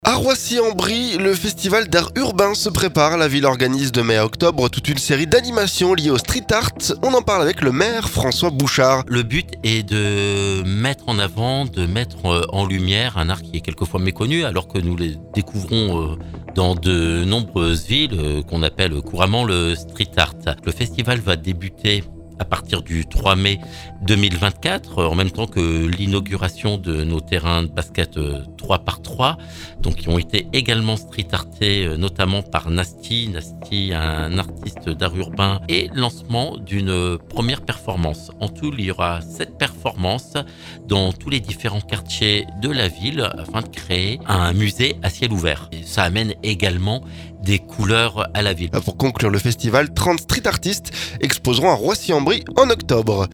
La ville organise de mai à octobre toute une série d'animations liées aux street art. On en parle avec le maire François Bouchart.